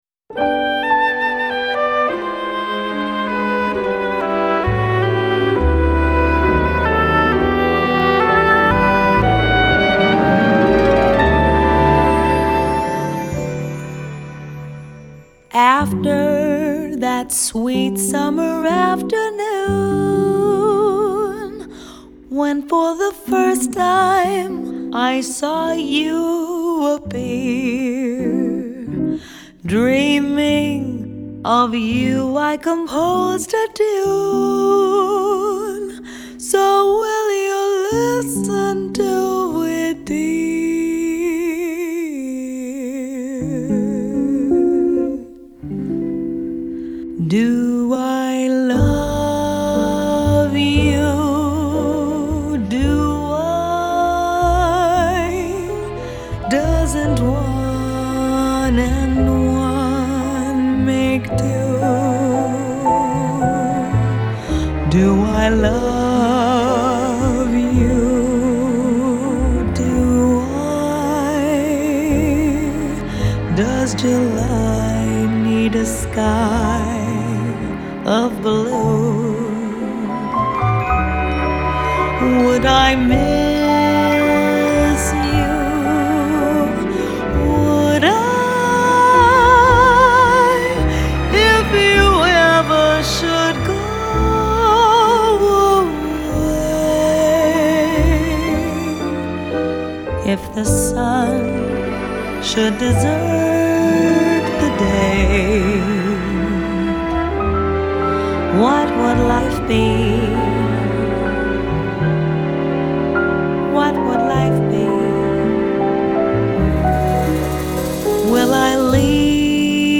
Genre : Vocal Jazz